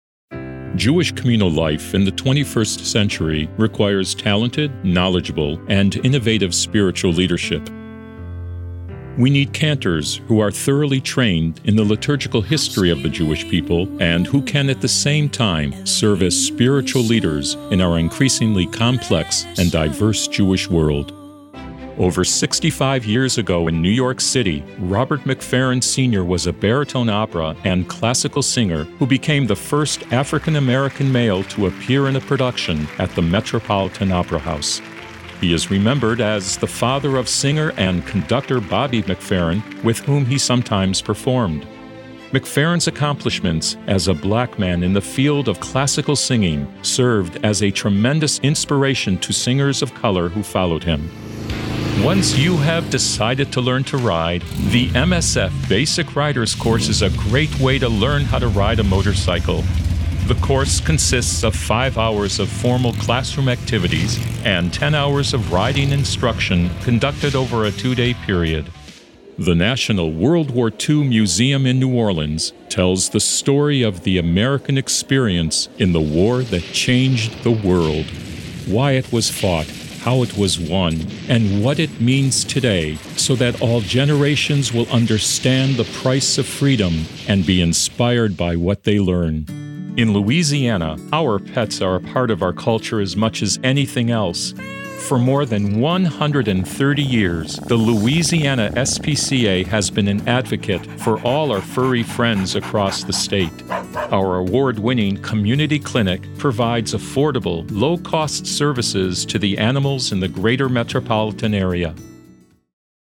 English (American)
Commercial, Deep, Senior, Mature, Friendly
Audio guide